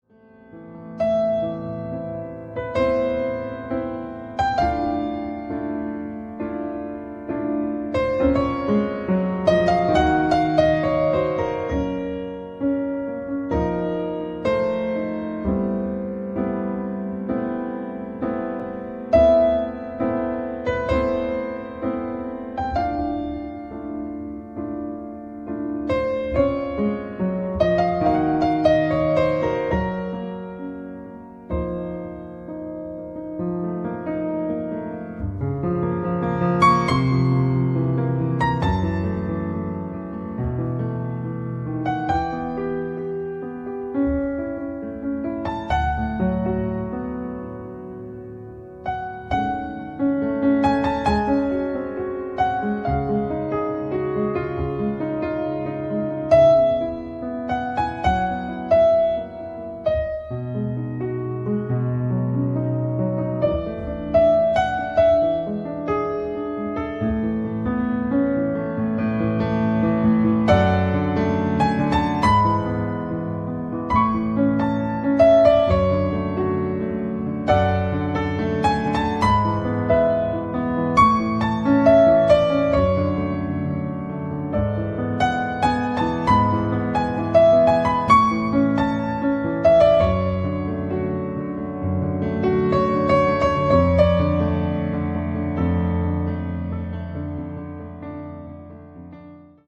丹精に磨き上げられたた珠玉のピアノ曲１３曲収録。
piano